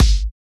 Kick Quasi 1.wav